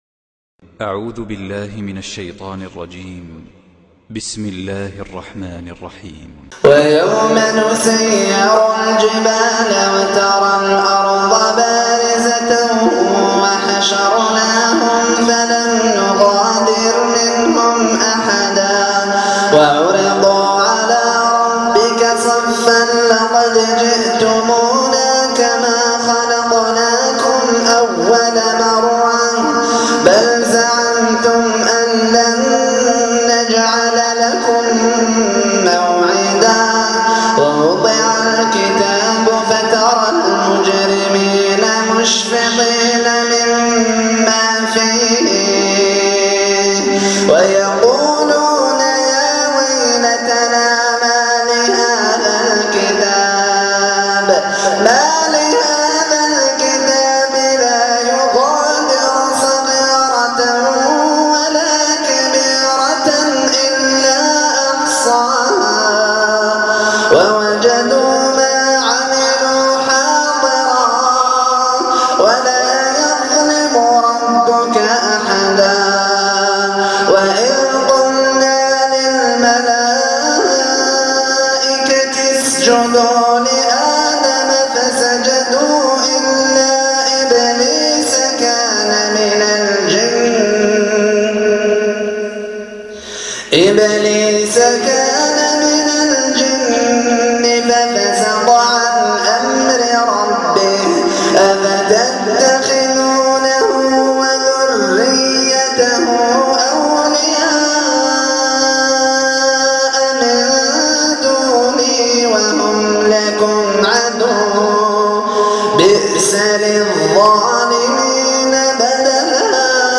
تلاوة رهيبة خاشعة مؤثرة